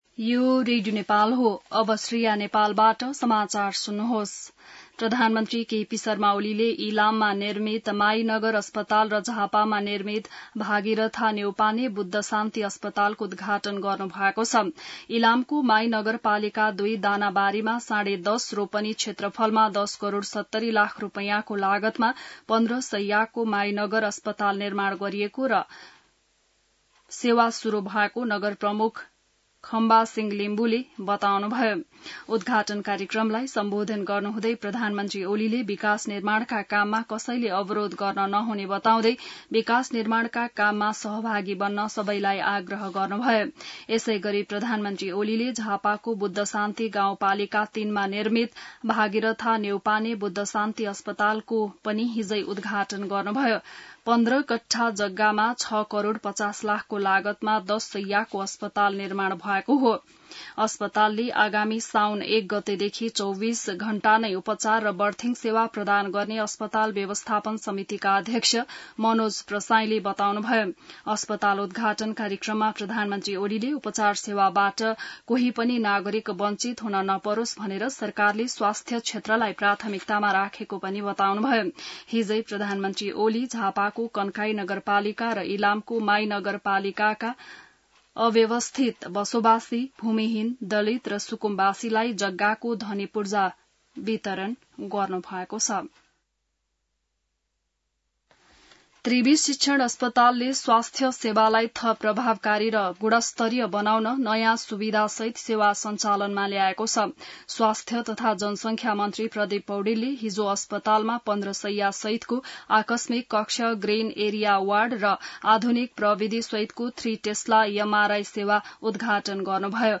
बिहान ६ बजेको नेपाली समाचार : २९ असार , २०८२